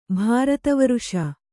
♪ bhārata varuṣa